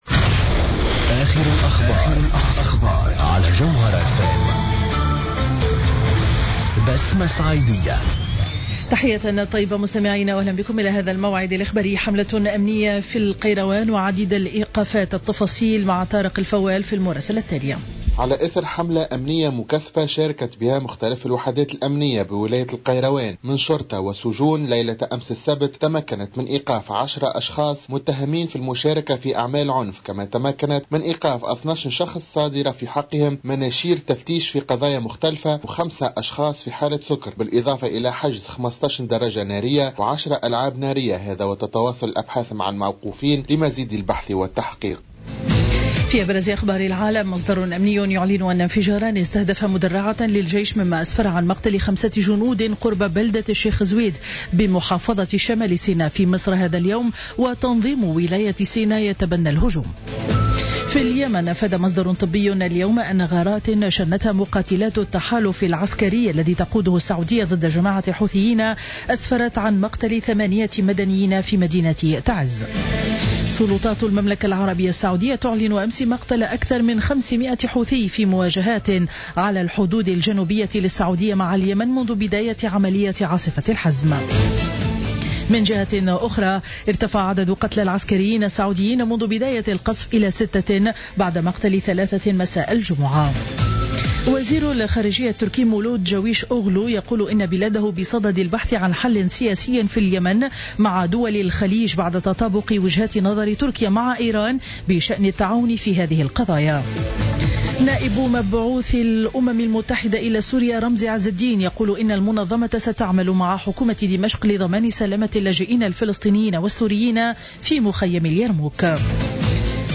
نشرة أخبار منتصف النهار ليوم الأحد 12 أفريل 2015